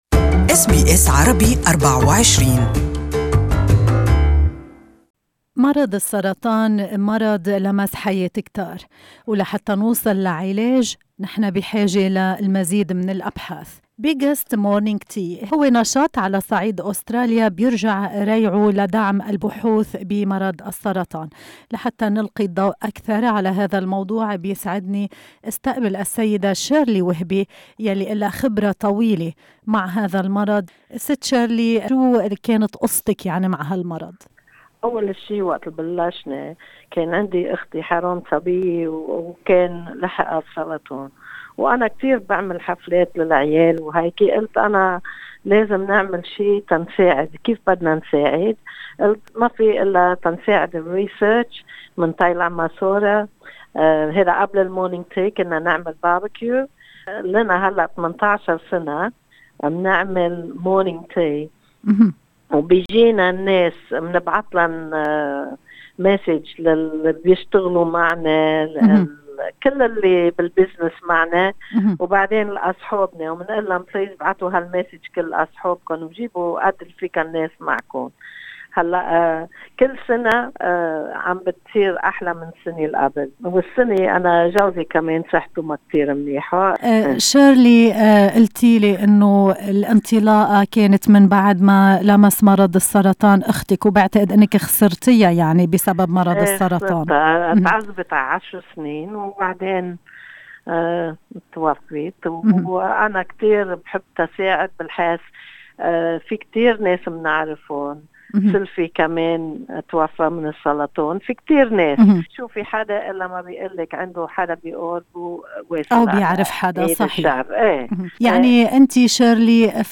المزيد من المعلومات في المقابلة الصوتية على الرابط أعلاه استمعوا هنا الى البث المباشر لاذاعتنا و لاذاعة BBC أيضا حمّل تطبيق أس بي أس الجديد على الأندرويد والآيفون للإستماع لبرامجكم المفضلة باللغة العربية.